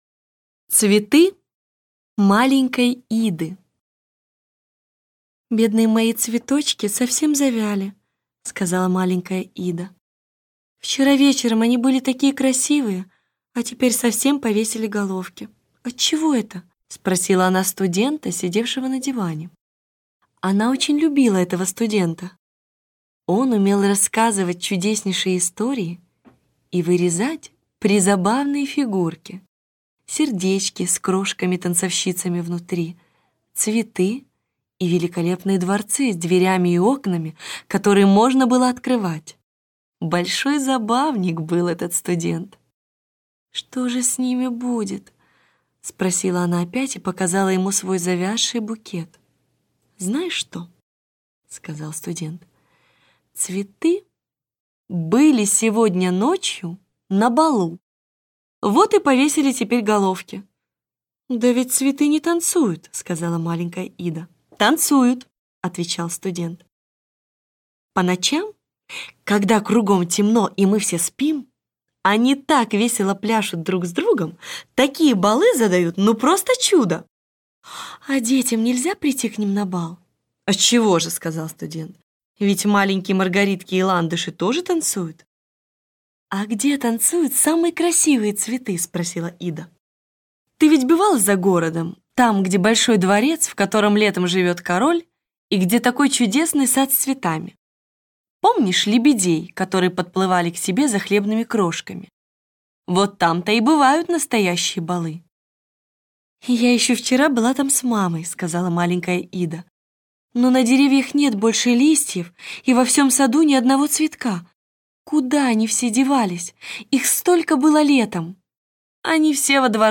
Аудио сказка «Цветы маленькой Иды» Андерсена.